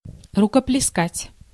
Ääntäminen
Synonyymit аплодировать зааплодировать Ääntäminen Tuntematon aksentti: IPA: /rʊkəplʲɪsˈkatʲ/ Haettu sana löytyi näillä lähdekielillä: venäjä Käännös Ääninäyte Verbit 1. applaud US 2. clap Translitterointi: rukopleskat.